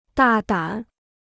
大胆 (dà dǎn): brazen; audacious.